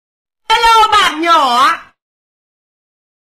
PewPew sound effects streamer sound effects voice ok